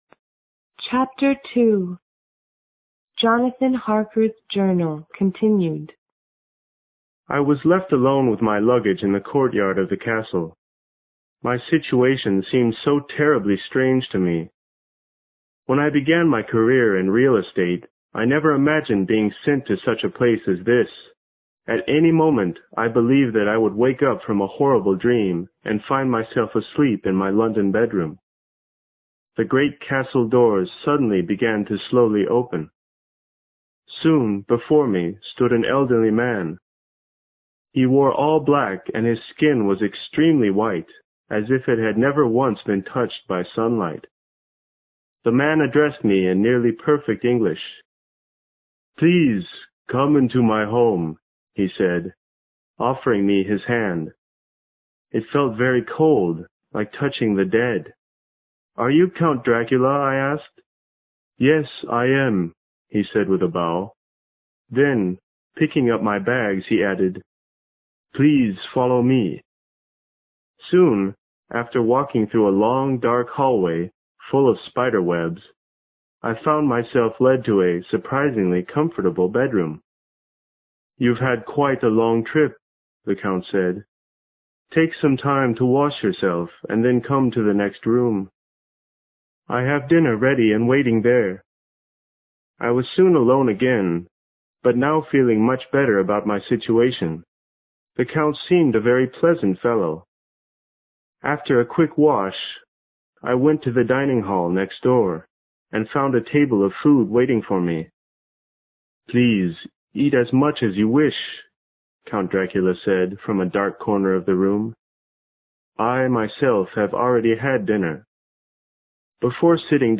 有声名著之吸血鬼 Chapter2 听力文件下载—在线英语听力室